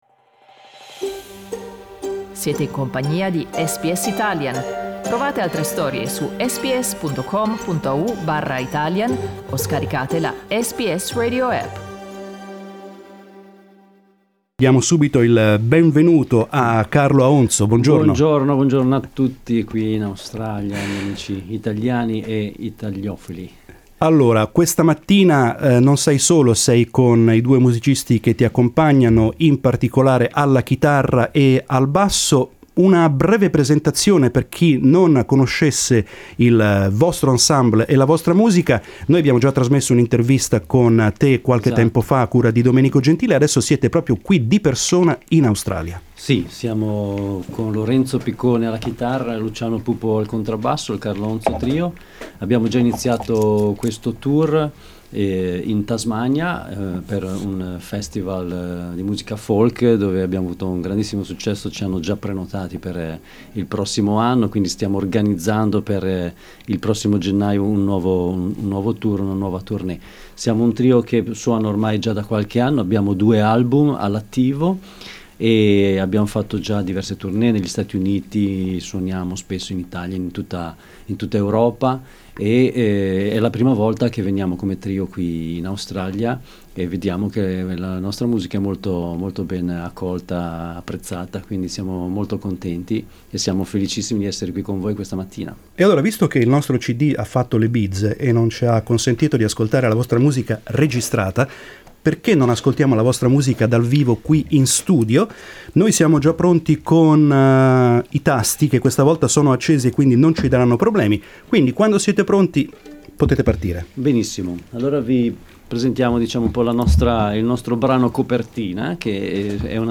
mandolin
guitar
bass